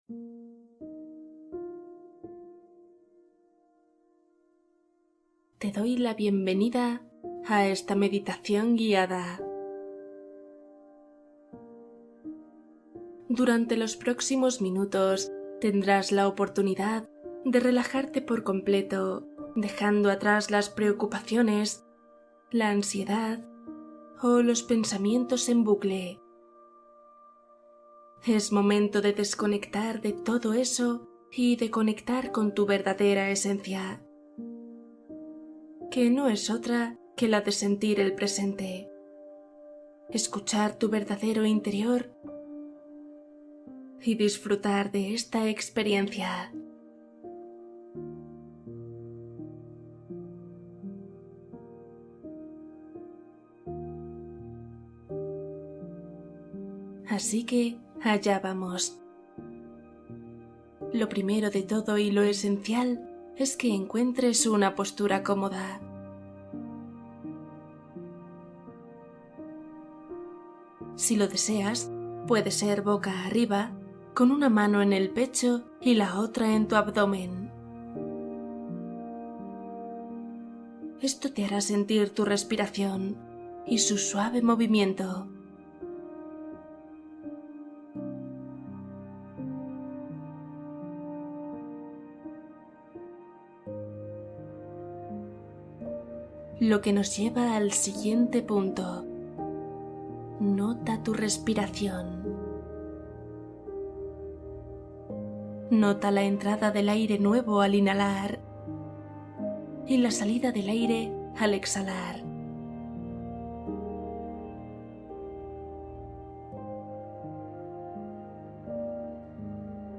El pozo de los deseos | Meditación para dormir y atraer abundancia